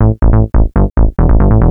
Techno / Bass / SNTHBASS148_TEKNO_140_A_SC2.wav
1 channel